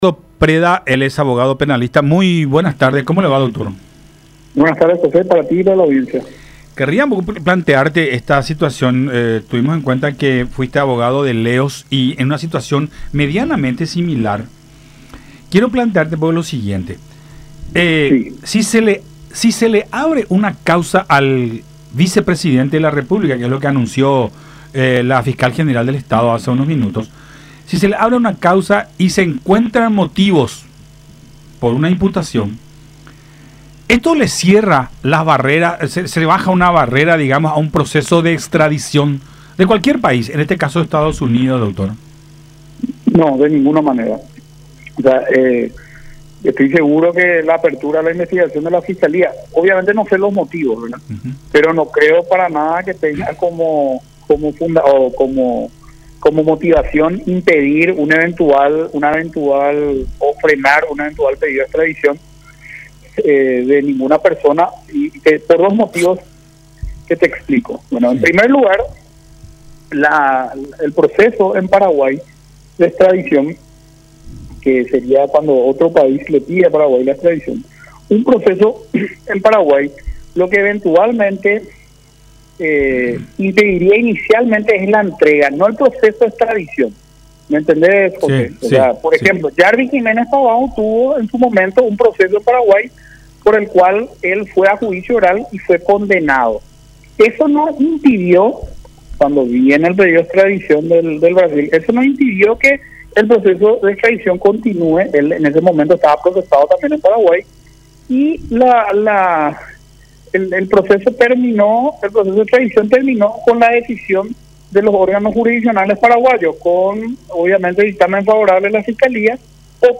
en diálogo con Buenas Tardes La Unión